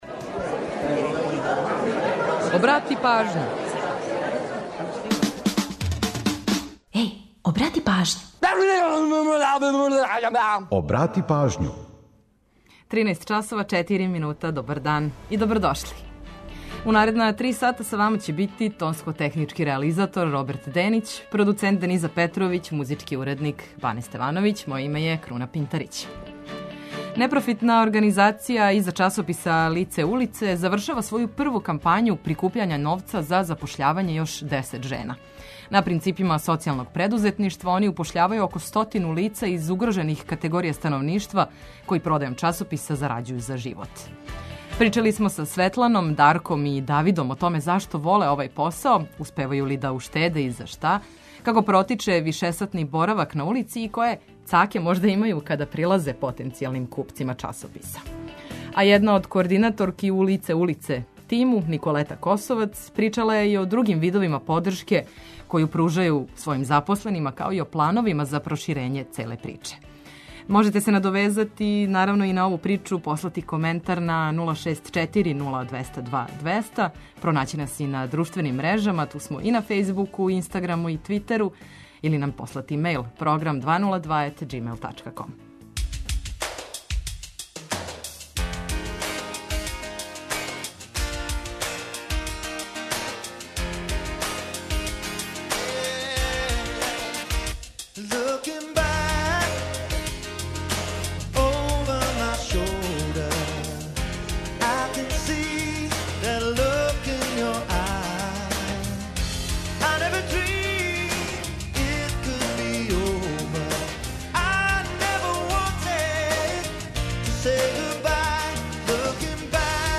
Корисне информације и омиљена музика су обавезни детаљ.